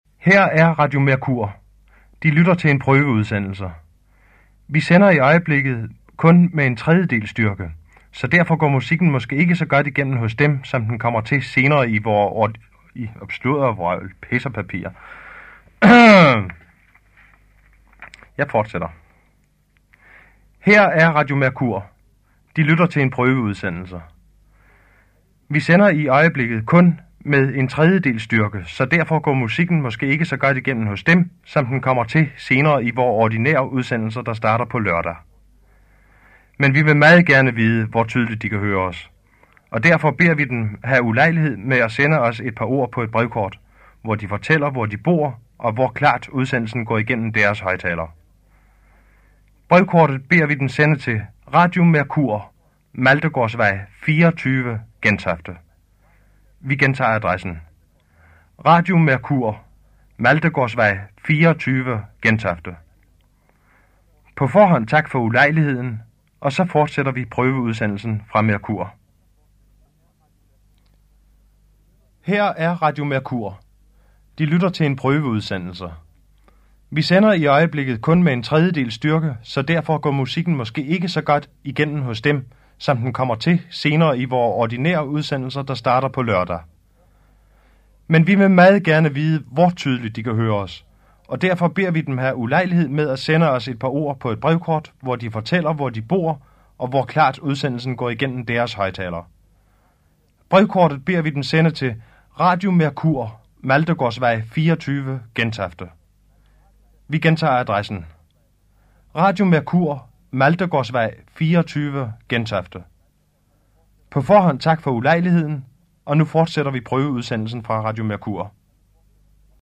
Test, från originalband, juni 1958.